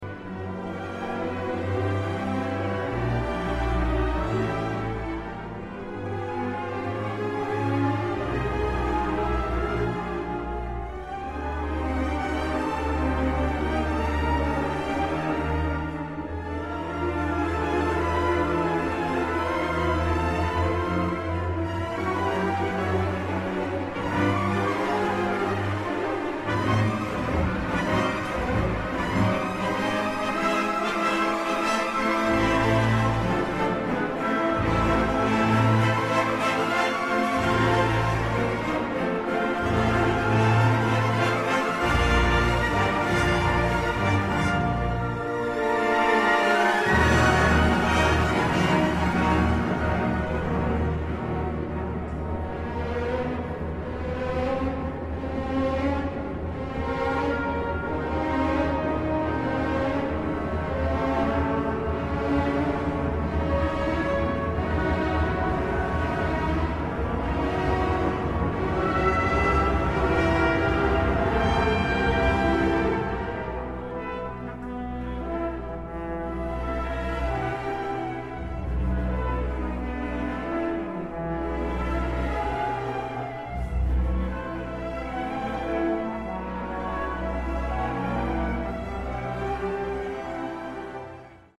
Disfruta de esta actuación de la Orquesta Sinfónica Juan José Landaeta